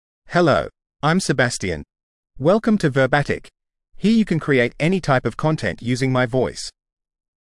Sebastian — Male English (Australia) AI Voice | TTS, Voice Cloning & Video | Verbatik AI
Sebastian is a male AI voice for English (Australia).
Voice sample
Male
Sebastian delivers clear pronunciation with authentic Australia English intonation, making your content sound professionally produced.